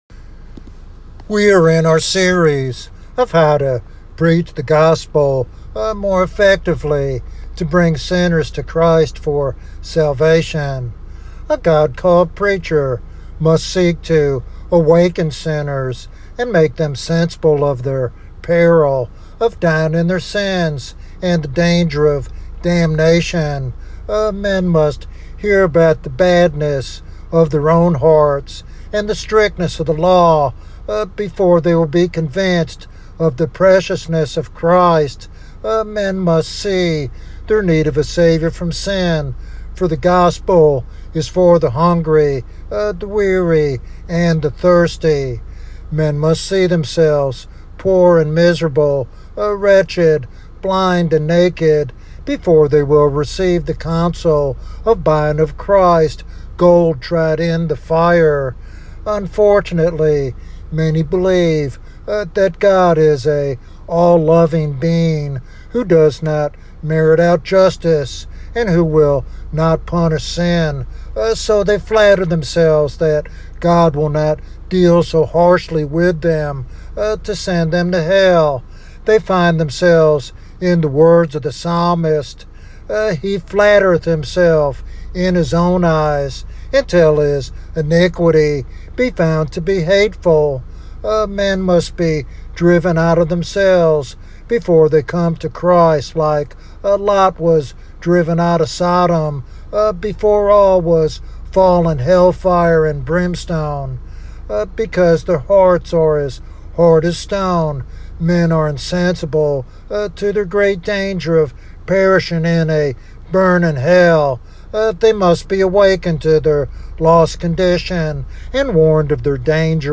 This evangelistic sermon challenges listeners to recognize their need for salvation and the incomparable value of Christ as the pearl of great price.
Sermon Outline